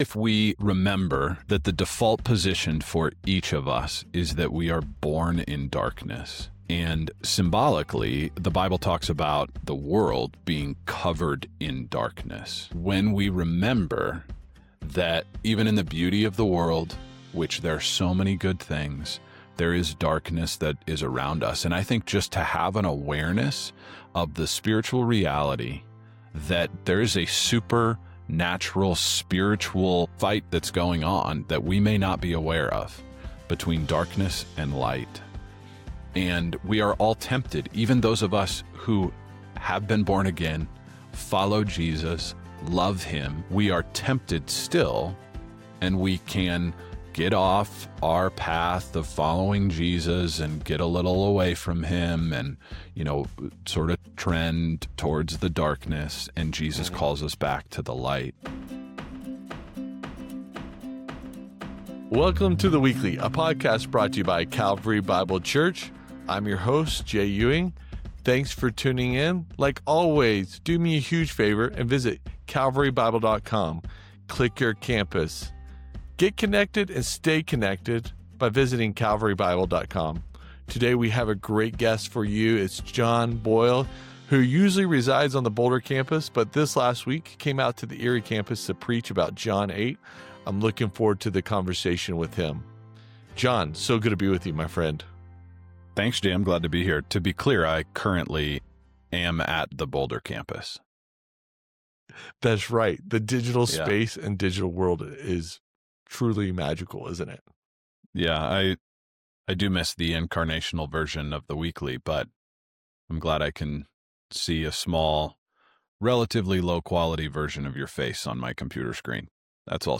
a thoughtful conversation through John 11, the story of Lazarus. Why did Jesus wait when the one He loved was sick?